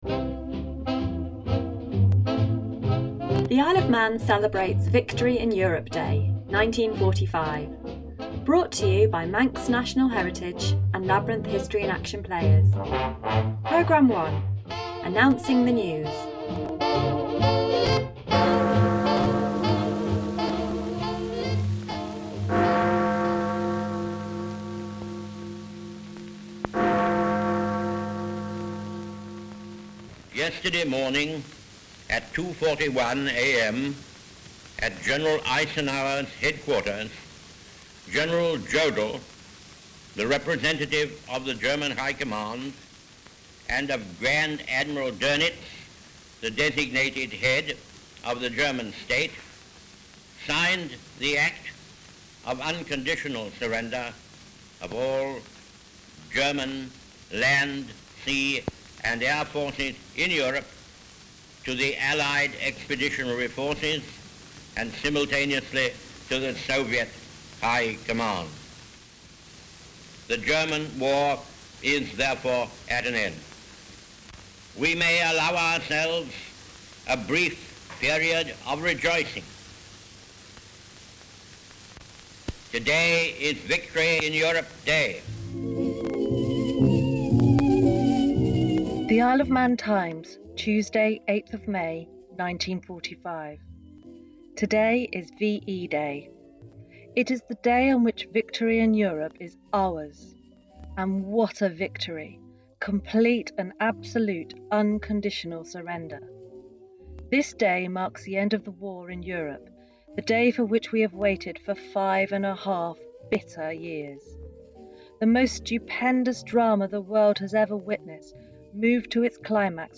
As we mark the 80th anniversary of VE Day, Manx National Heritage Labyrinth History in Action Players bring you some of the sounds and reports of VE Day in the IOM.